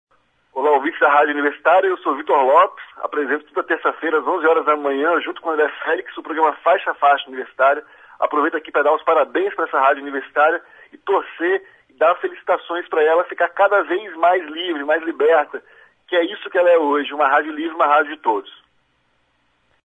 Confira os depoimentos: